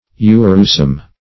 Search Result for " urosome" : The Collaborative International Dictionary of English v.0.48: Urosome \U"ro*some\, n. [2d uro- + -some body.]